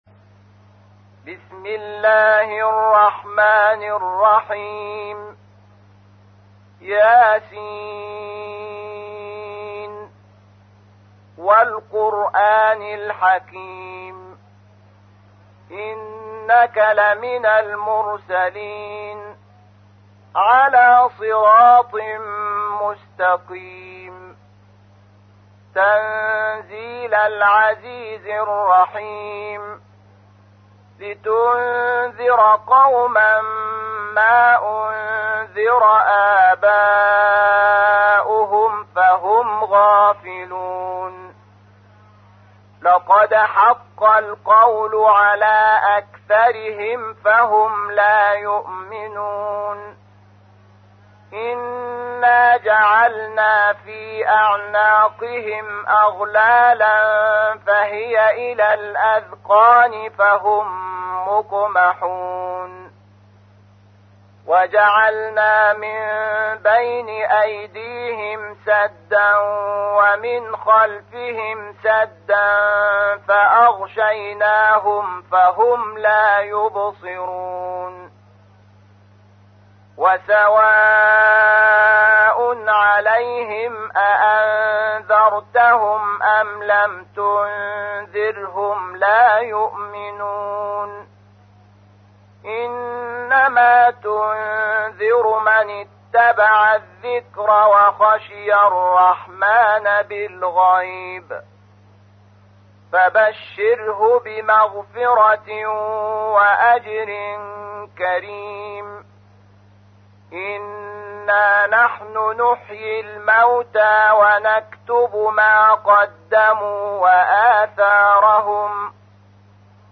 تحميل : 36. سورة يس / القارئ شحات محمد انور / القرآن الكريم / موقع يا حسين